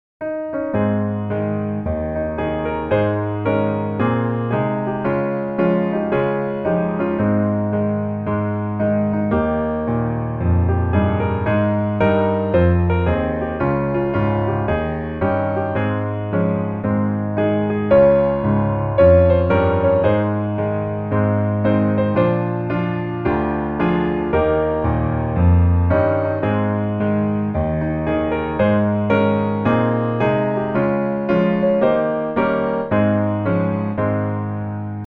Ab大調